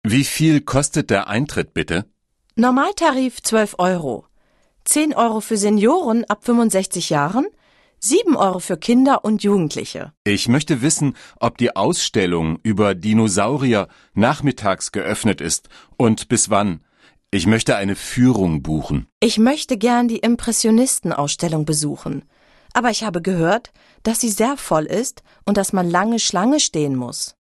Un peu de conversation - Les musées